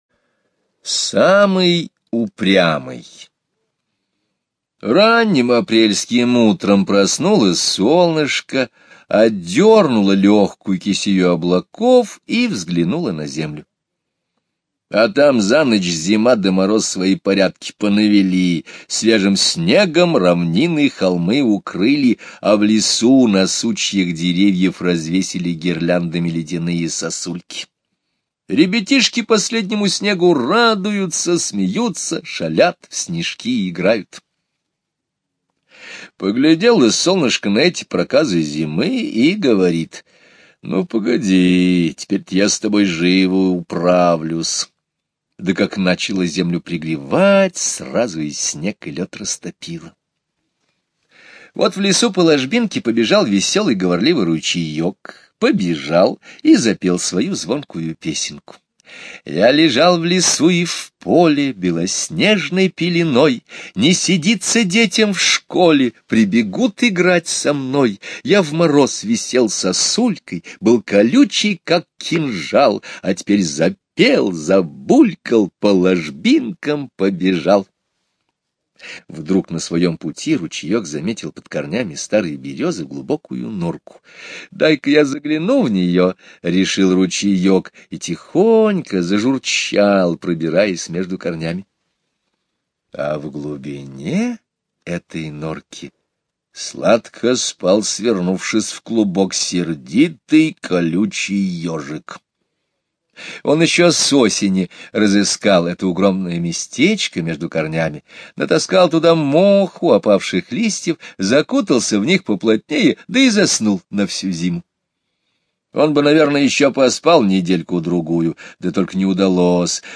Слушайте Самый упрямый - аудио рассказ Скребицкого Г. Рассказ про весенние дни: солнце растапливает последний снег, просыпаются животные.